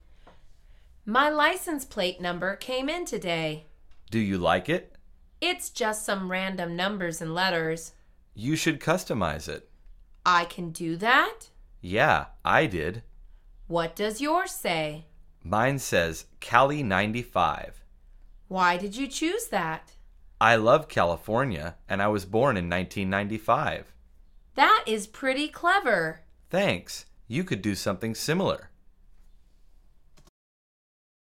مجموعه مکالمات ساده و آسان انگلیسی – درس شماره دهم از فصل رانندگی: پلاک سفارشی